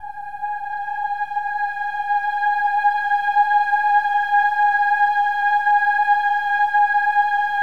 OH-AH  G#5-L.wav